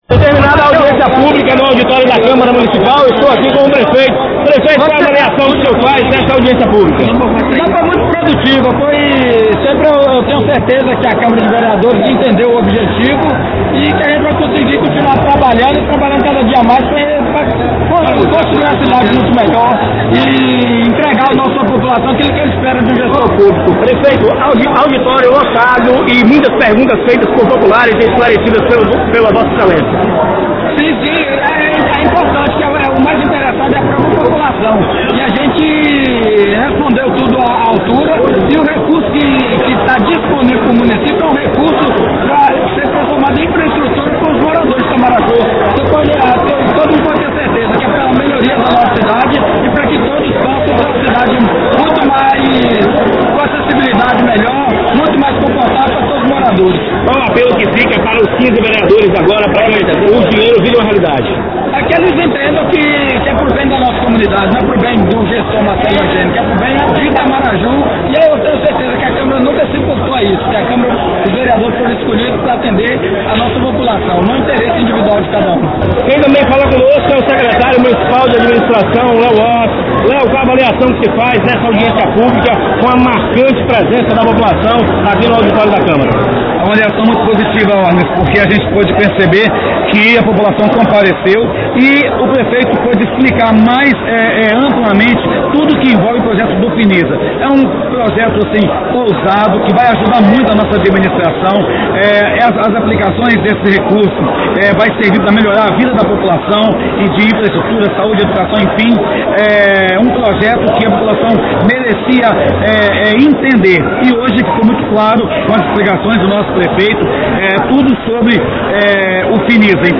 Ao término do evento nossa reportagem conversou com algumas autoridades que estiveram presentes e fizeram breve avaliação do evento ocorrido na última quinta-feira-play